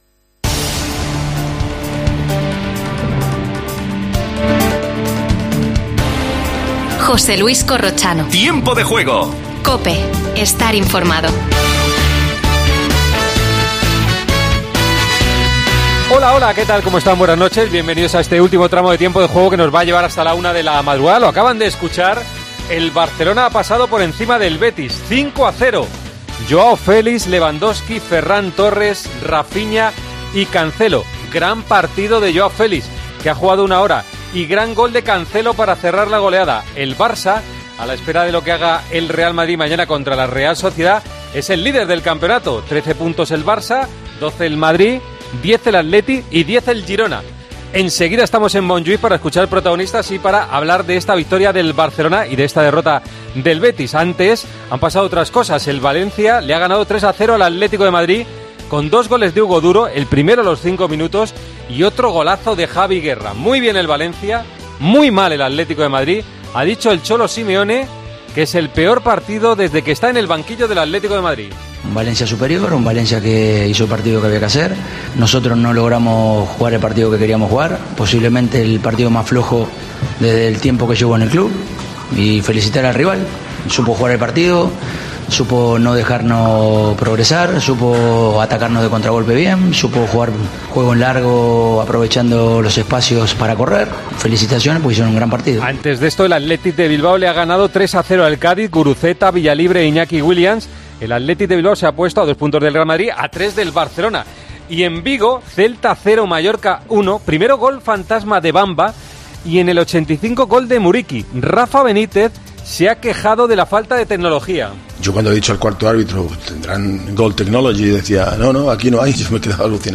Indicatiu del programa, resum dels parits de primera divisió que ja s'havien disputat, notícies poliesportives, tema musical, informació des de l'Estadi de Montjuïc del partit del Fútbol Club Barcelona - Betis (5 -0)
Gènere radiofònic Esportiu